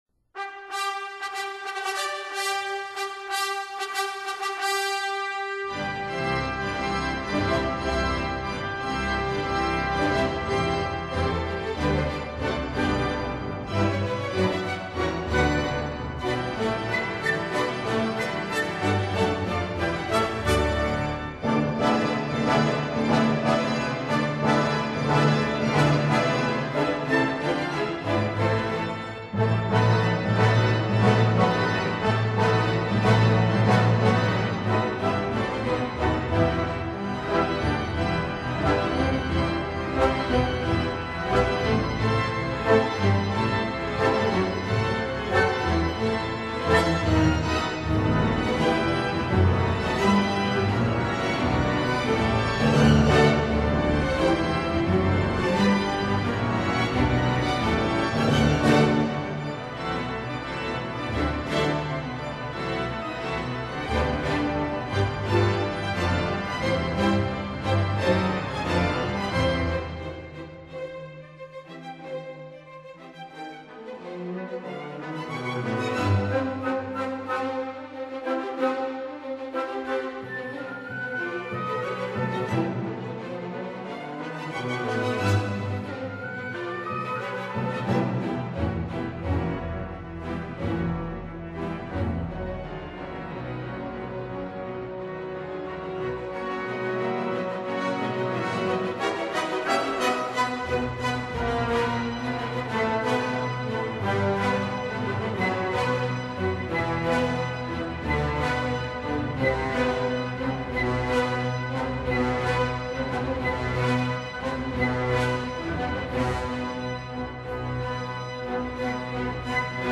•(01) Symphony for organ & orchestra, Op. 42bis
organ